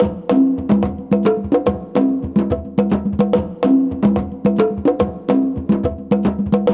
CONGBEAT10-R.wav